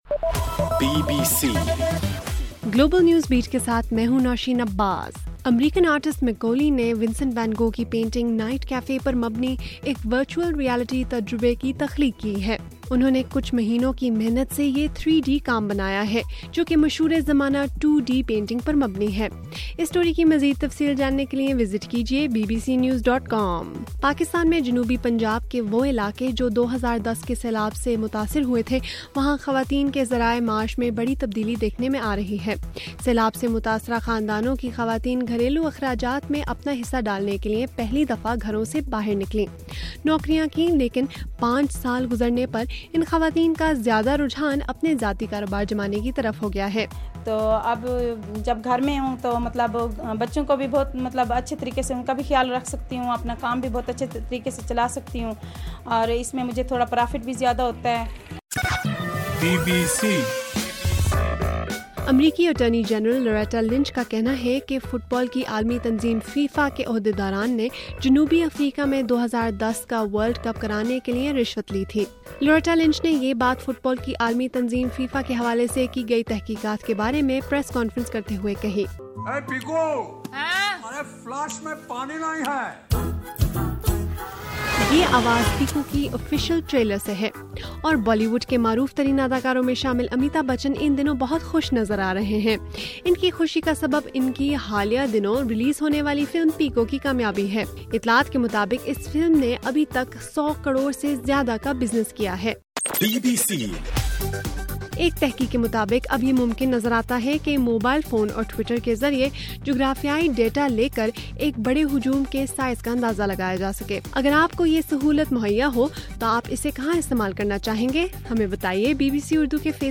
مئی 28:صبح1 بجے کا گلوبل نیوز بیٹ بُلیٹن